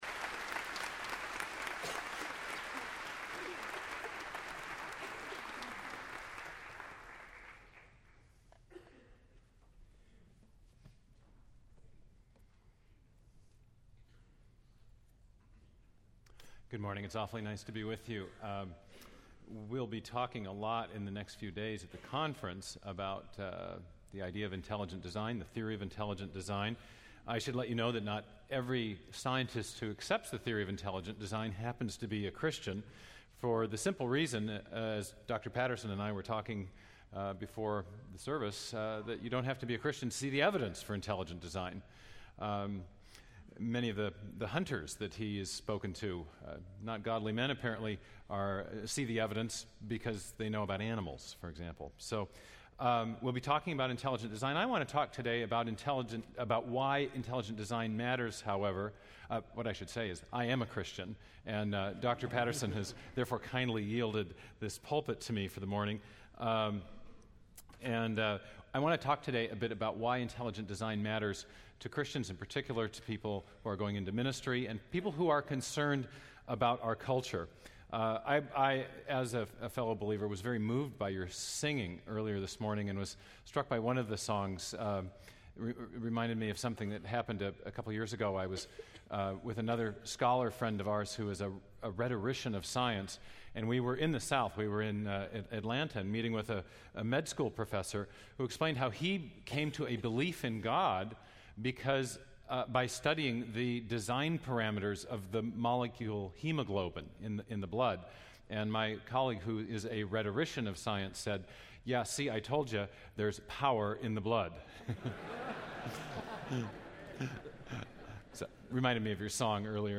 Dr. Stephen Meyer speaking on Intelligent Design in SWBTS Chapel on Thursday October 22, 2009